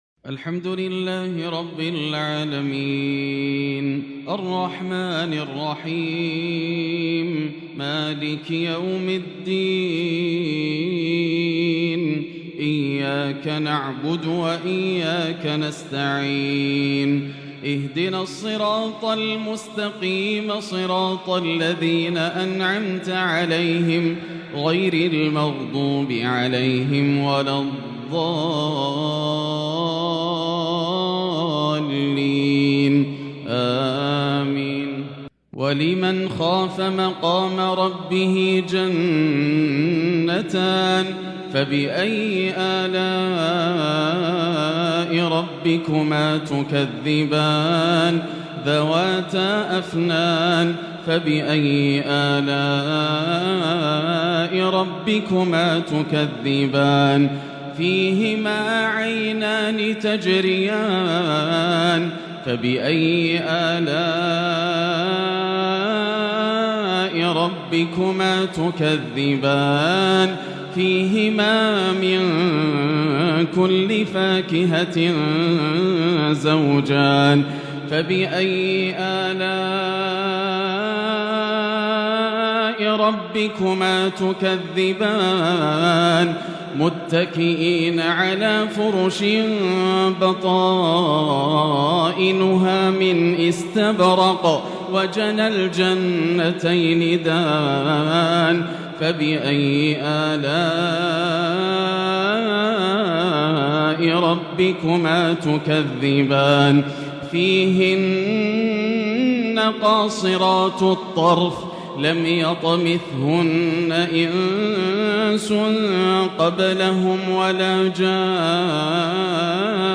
صلاة الميت بالحرم المكي على الشيخ خليفة بن زايد رئيس دولة الامارات | تلاوة لوصف الجنة د.ياسر الدوسري > تلاوات عام 1443هـ > مزامير الفرقان > المزيد - تلاوات الحرمين